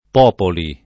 [ p 0 poli ]